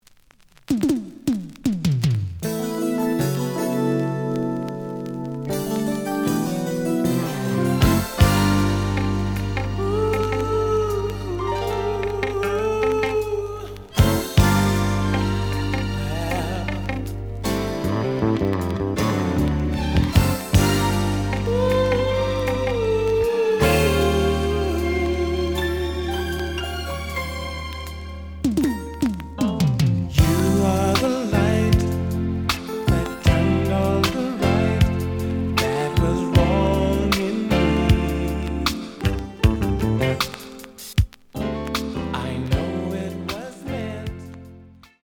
The audio sample is recorded from the actual item.
●Genre: Funk, 80's / 90's Funk
Looks good, but slight noise on B side.)